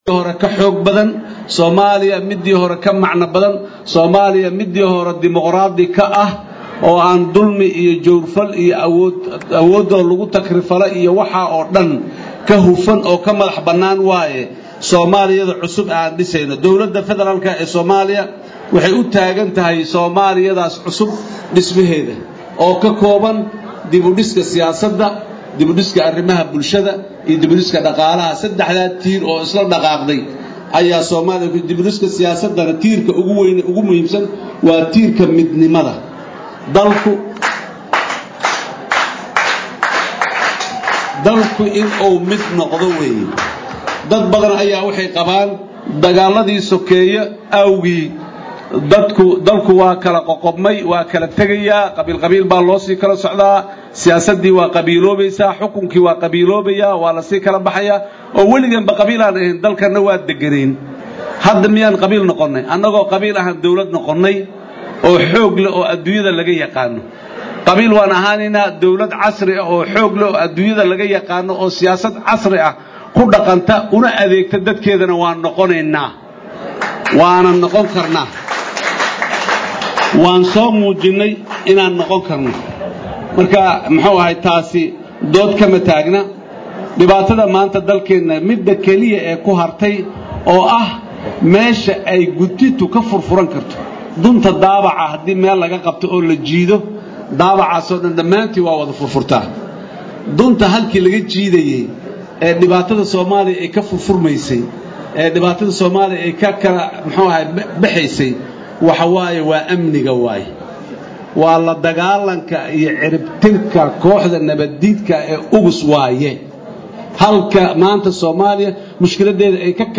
Dhageyso: Khudbadii Madaxweyne Xasan Uu Xaley Ka Jeediyey Xuska 1da Luulyo
Muqdisho-(INO)–Madaxweynada Jamhuuriyada Federaaliga ah ee Soomaaliya Mudane Xasan Sh. Max’uud oo xalay ka hadlayay Munaasibadda 1da Julay ee ku beegan Xuska Madaxbanaanida Gobolladada Koofurta iyo israacii labada Gobol ee Waqooyiga iyo Koofurta Dalka.